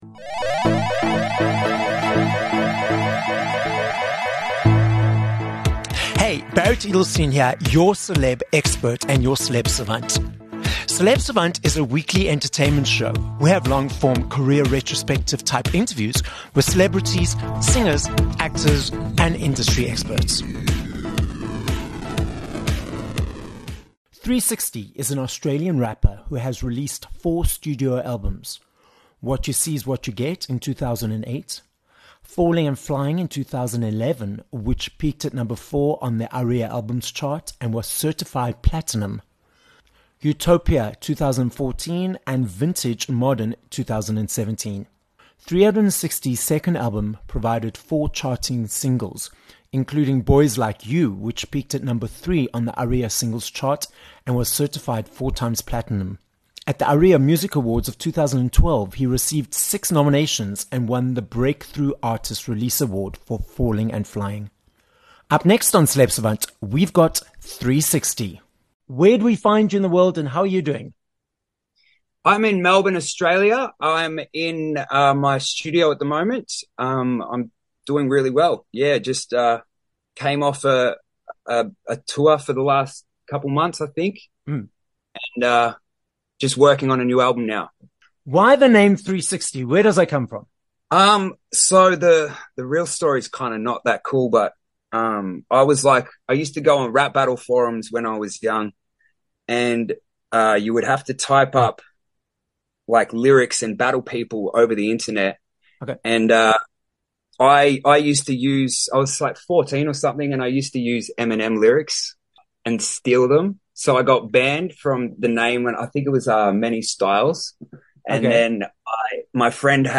21 Jan Interview with 360
We head down under to Australia on this episode of Celeb Savant, as we are joined by Australian rapper, 360. We hear where the name 360 comes from, what rap battles are, and how he created a successful career in music after starting out in these battles as a teenager.